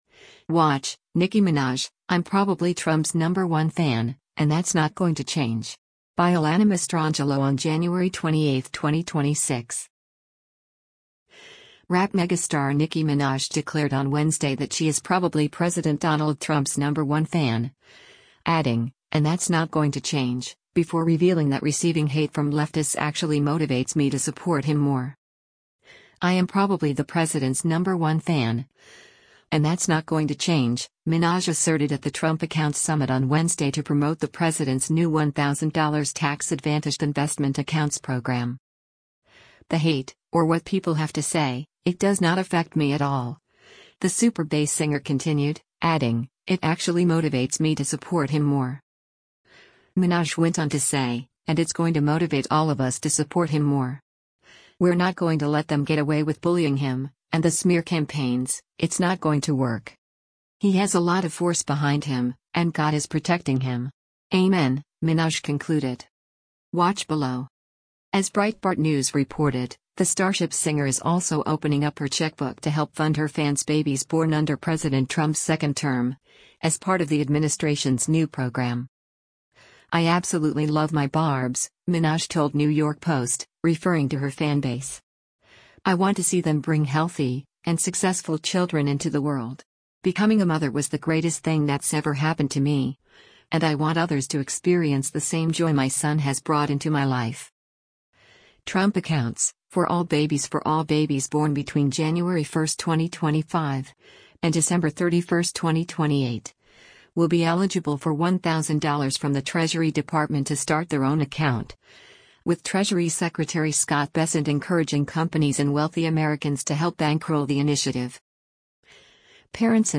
“I am probably the president’s number one fan, and that’s not going to change,” Minaj asserted at the Trump Accounts summit on Wednesday to promote the president’s new $1,000 tax-advantaged investment accounts program.